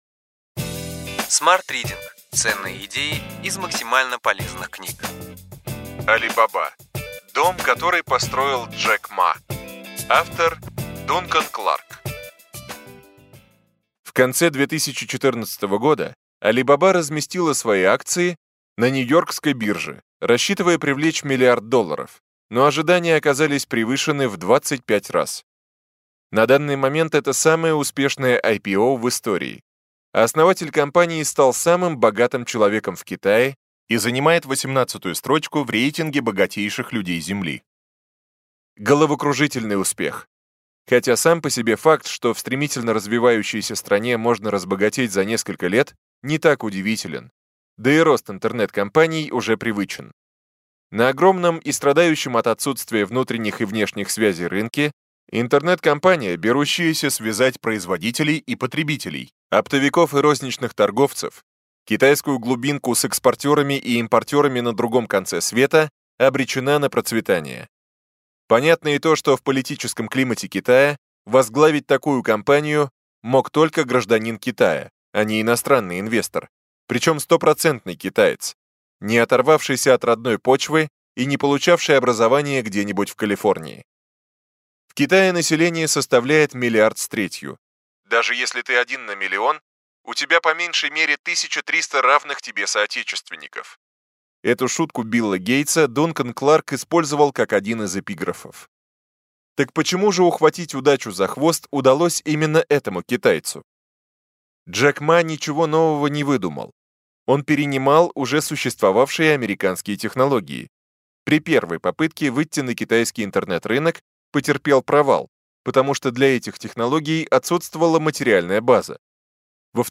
Аудиокнига Ключевые идеи книги: Алибаба. Дом, который построил Джек Ма.